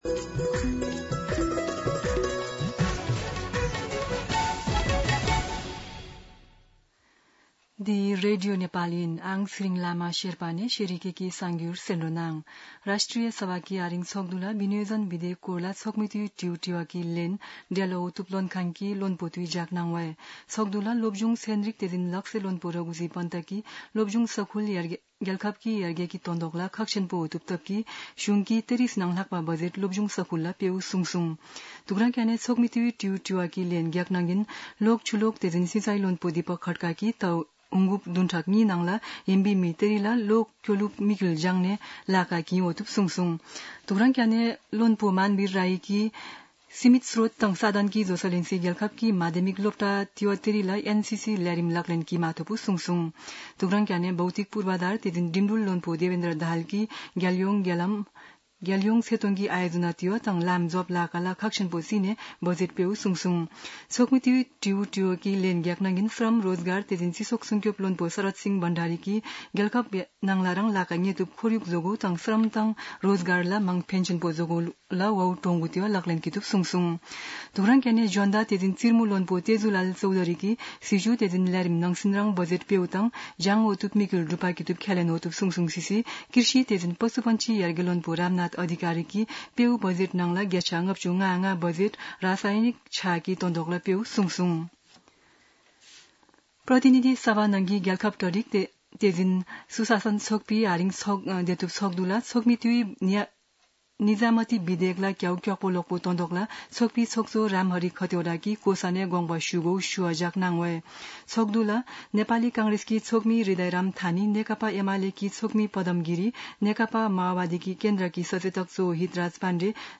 शेर्पा भाषाको समाचार : १८ असार , २०८२
Sherpa-News-3-18.mp3